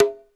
Conga_2.wav